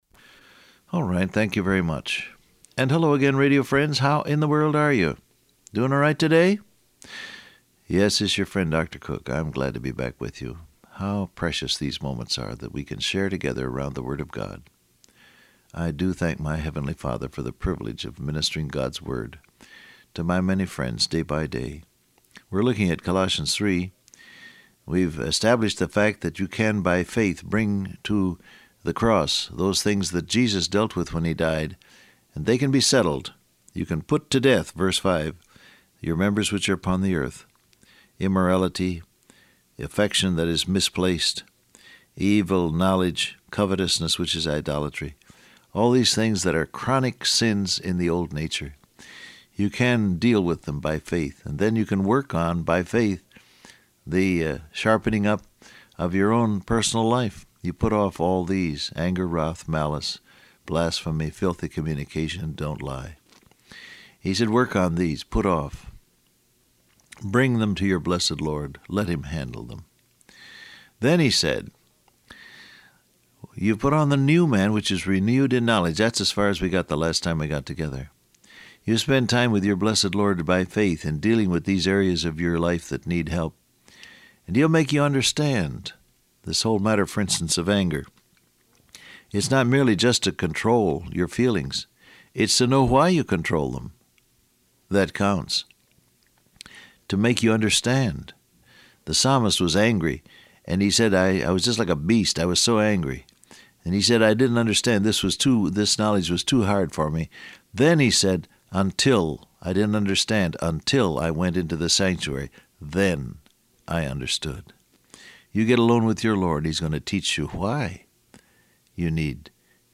Download Audio Print Broadcast #1909 Scripture: Colossians 3:12-13 , Ephesians 4 Transcript Facebook Twitter WhatsApp Alright, thank you very much.